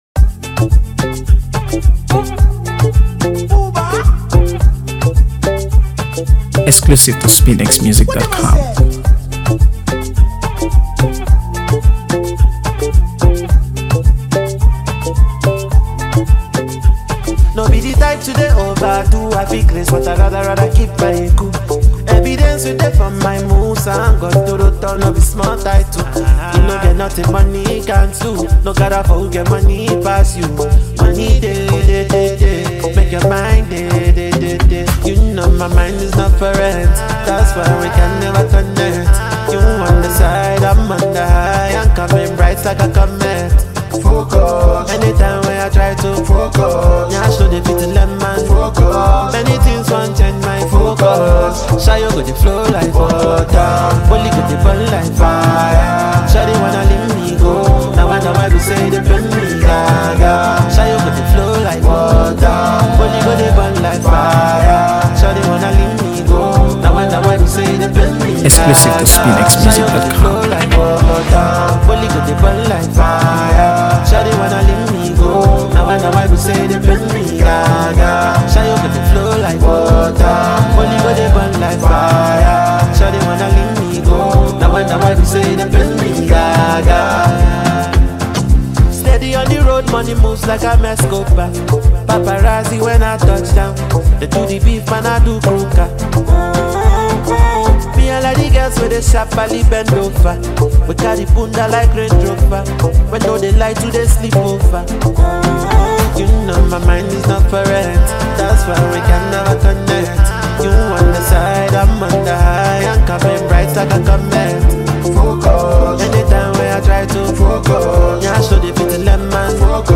AfroBeats | AfroBeats songs
With its soulful undertones and minimalistic instrumentation